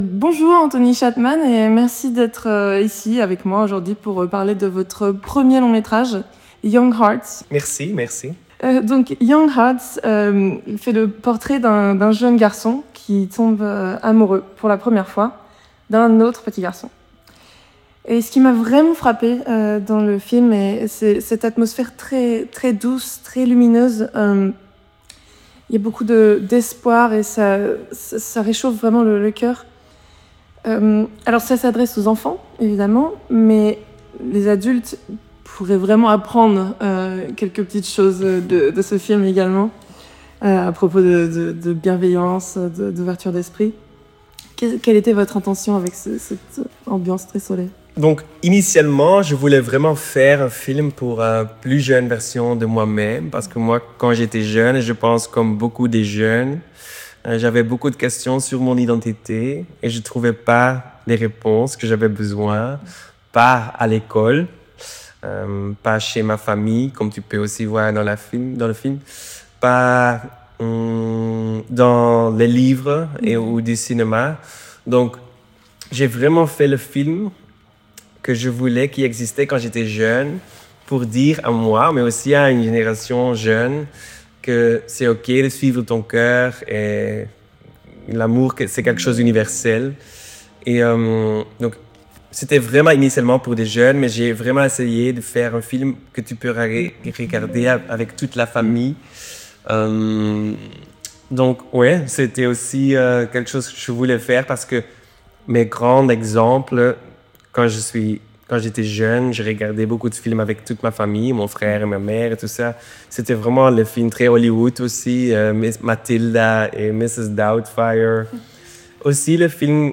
%%Les podcasts, interviews, critiques, chroniques de la RADIO DU CINEMA%% La Radio du Cinéma